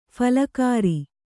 ♪ phalakāri